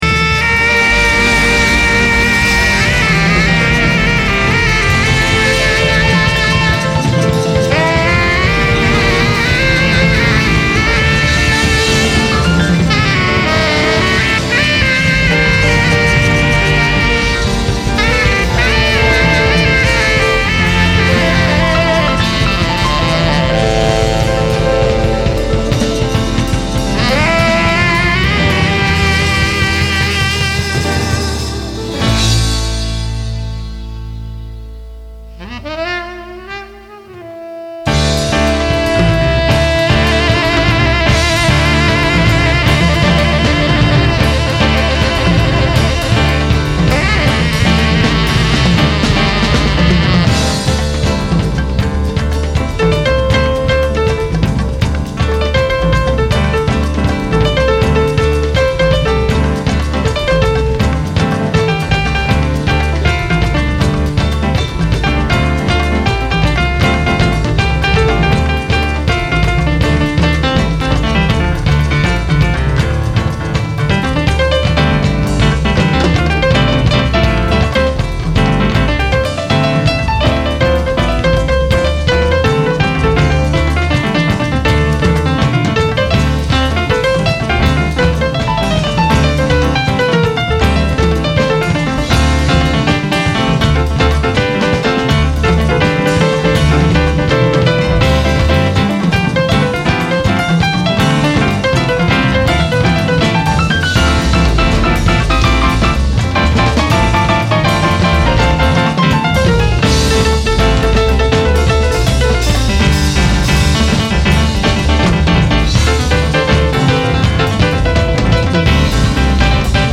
voce, chitarra, tiple, charango
percussioni
batteria
sax tenore e soprano. flauti
Registrato a Milano - CSOA Leoncavallo il 7 marzo 2000
e al Trieste -Teatro Miela il 23 marzo 2000